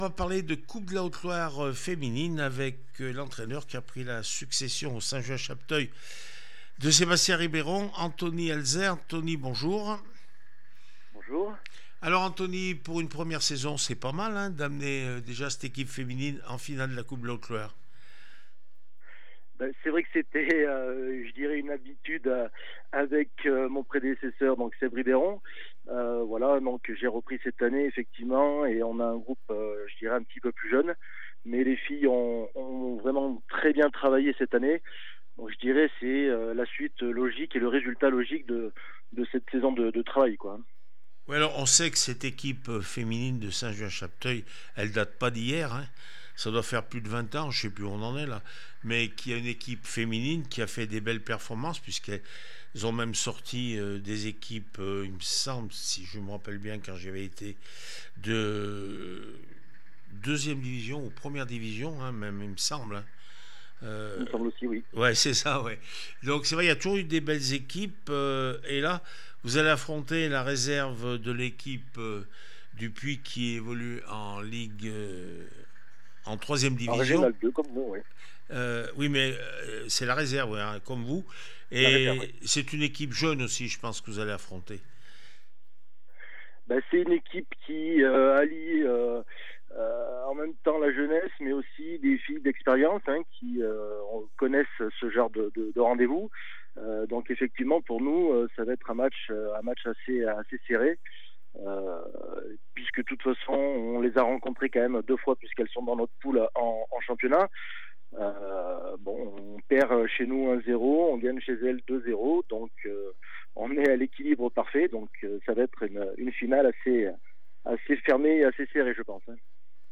21 juin 2025   1 - Sport, 1 - Vos interviews